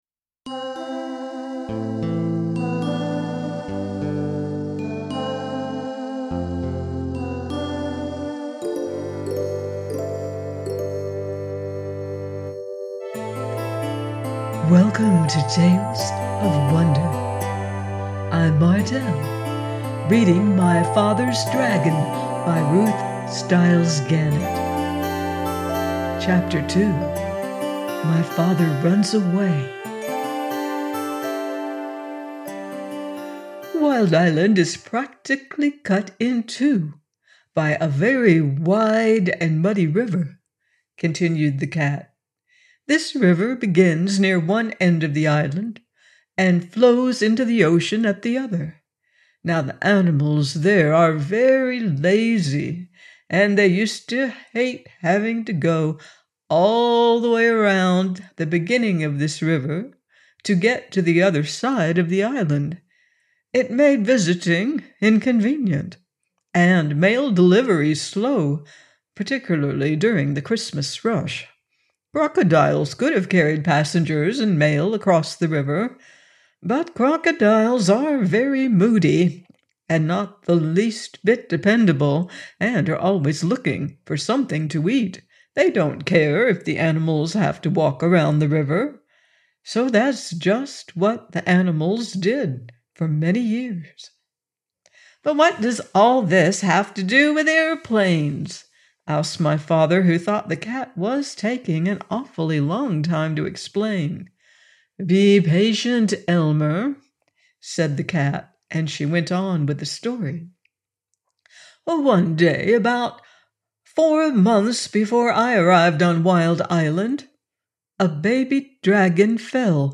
My Father’s Dragon – 02- by Ruth Styles Gannett - audiobook